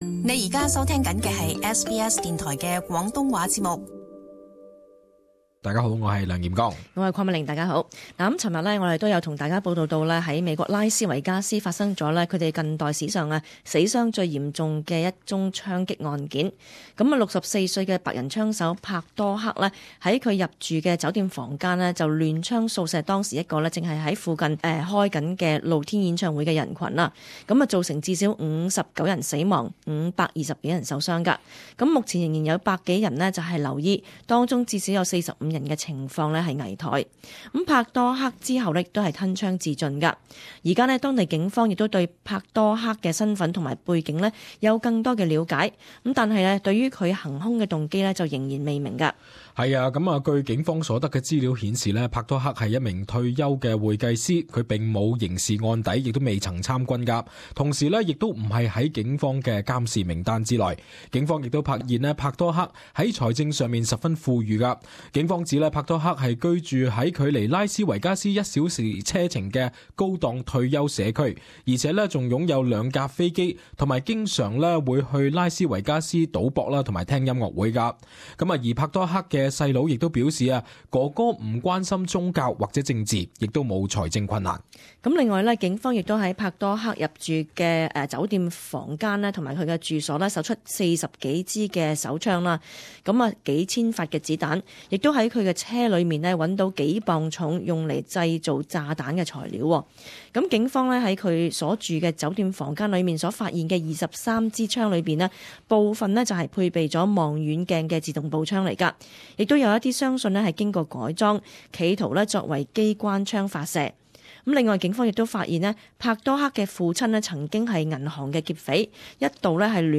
【時事報導】拉斯維加斯槍擊案疑兇動機未明